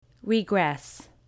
Location: USA
Used as a verb, listen here, it means: To move backward; To go back; To revert to an earlier or less advanced state or form.